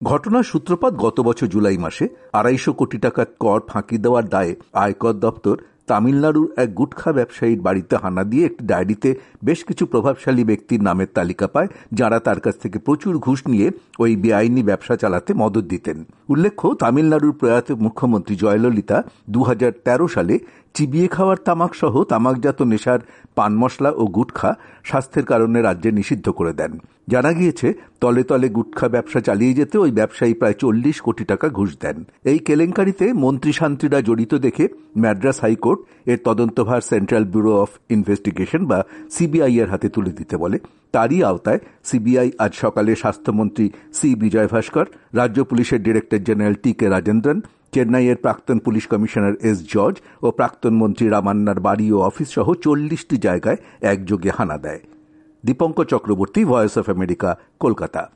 প্রতিবেদন